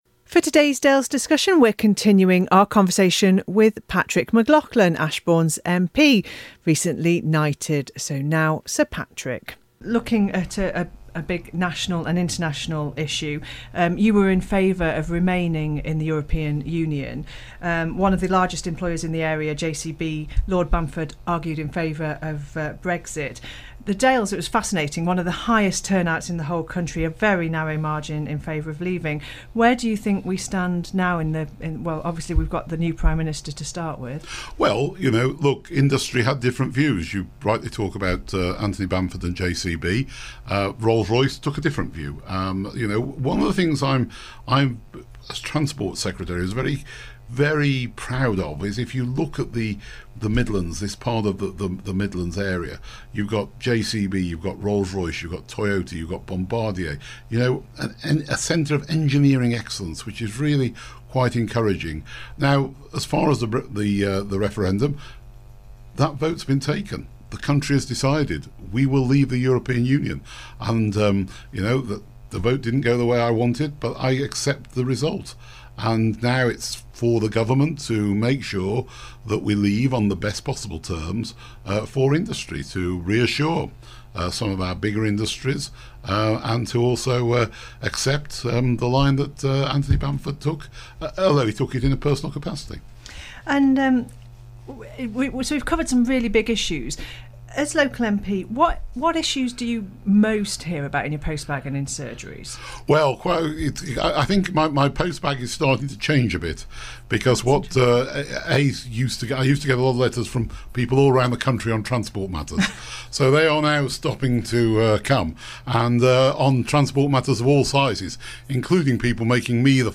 In the second part of our interview with Derbyshire Dales MP Patrick McCloughlin we talk about the EU referendum and also his personal life from being a miner to becoming a Knight of the Realm.